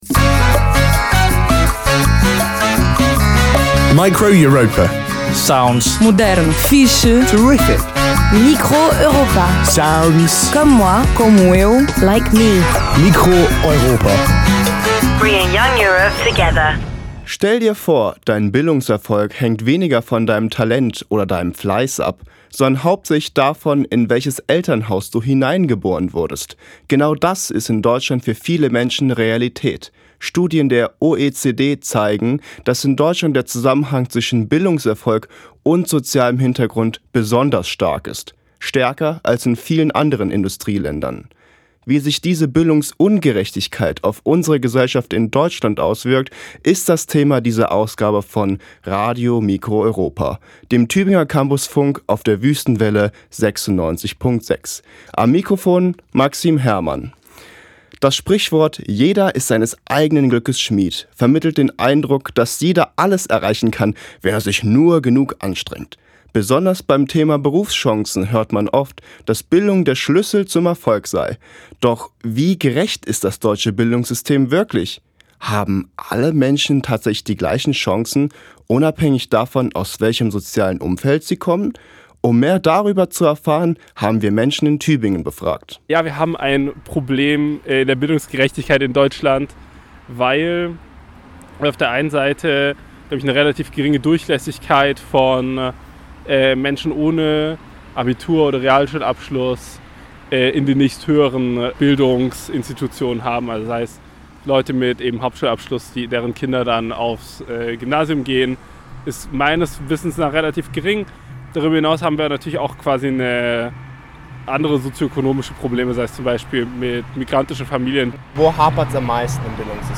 Form: Live-Aufzeichnung, geschnitten
Um diese Fragen zu klären, haben wir Passanten in Tübingen auf der Straße befragt.